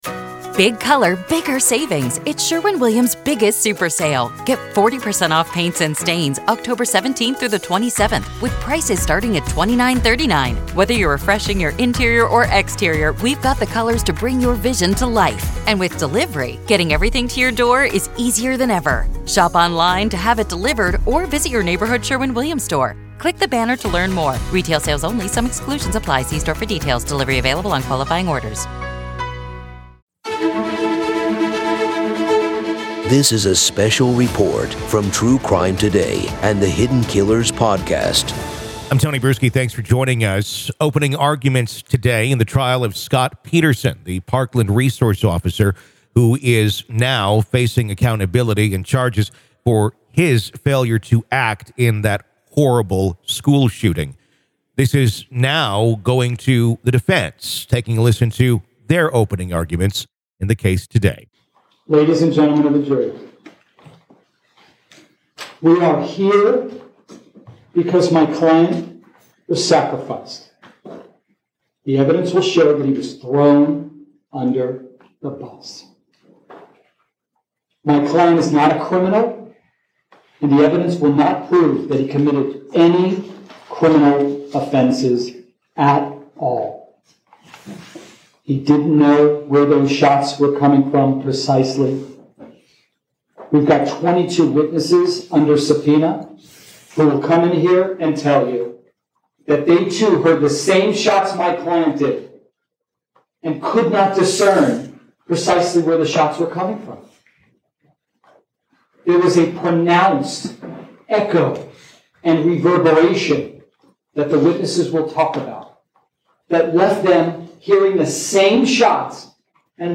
The prosecution outlines its case against Peterson, elucidating the charges and detailing the officer's perceived inaction during the massacre. This recording offers listeners an unfiltered glimpse into the courtroom, revealing the intensity and gravity of such high-stake legal proceedings.